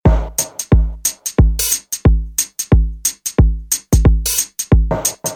reggae drumloops soundbank 2